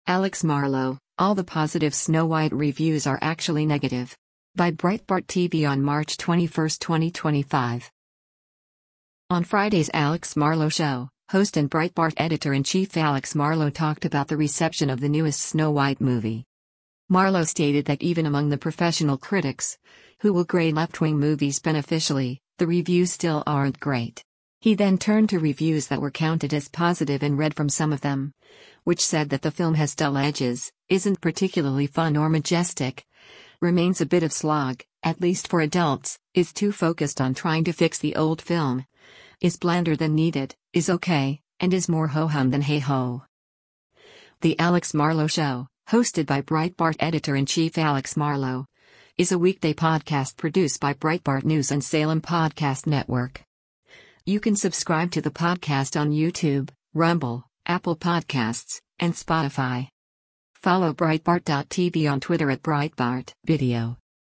On Friday’s “Alex Marlow Show,” host and Breitbart Editor-in-Chief Alex Marlow talked about the reception of the newest “Snow White” movie.